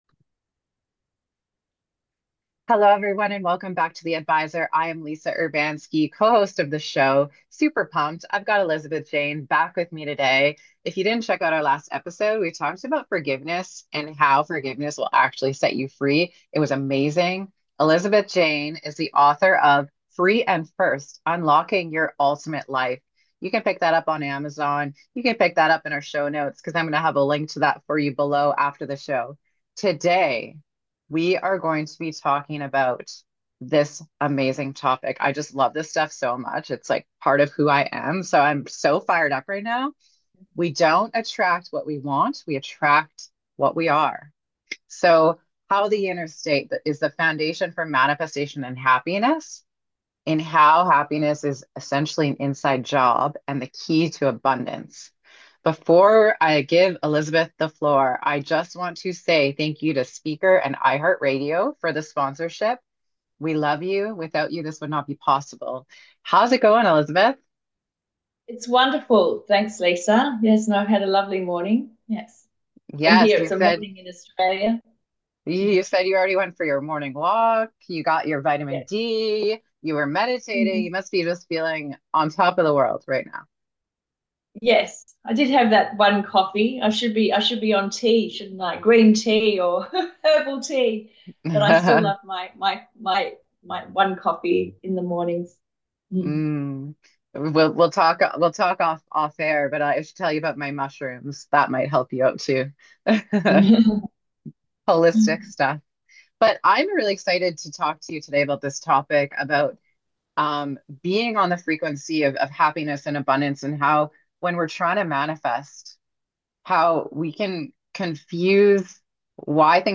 a profound conversation